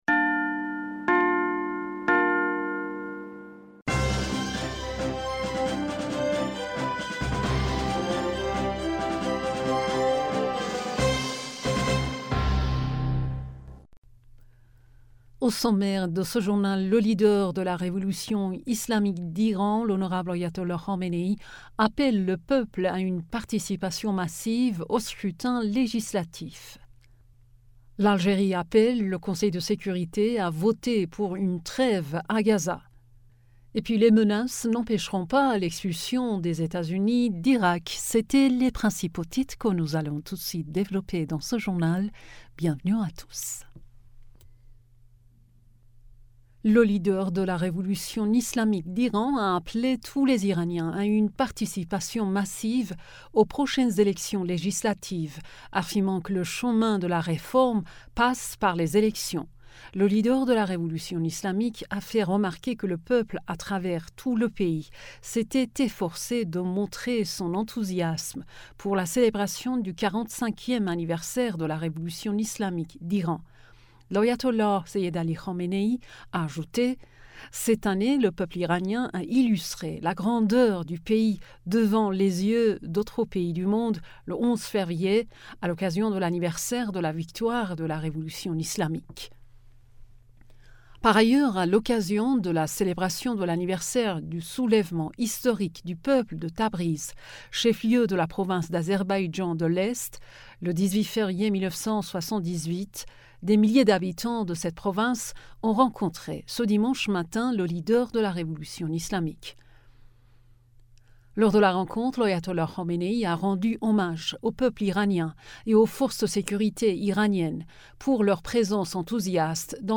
Bulletin d'information du 18 Fevrier 2024